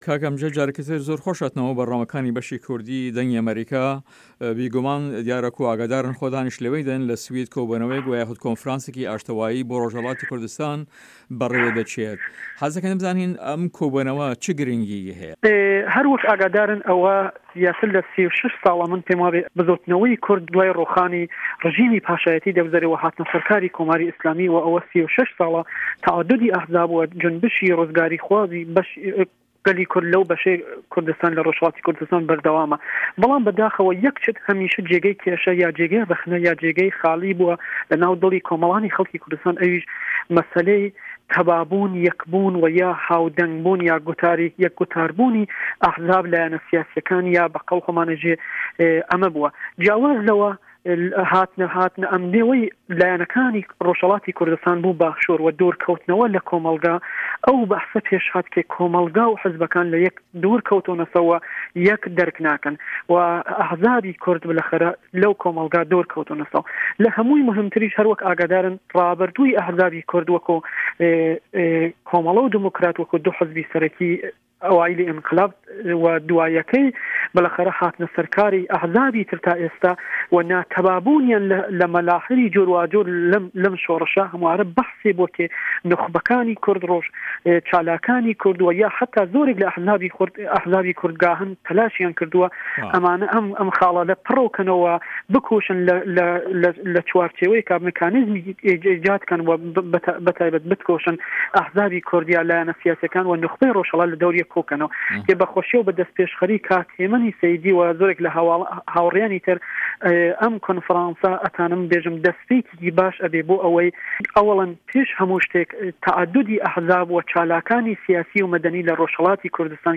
هه‌رێمه‌ کوردیـیه‌کان - گفتوگۆکان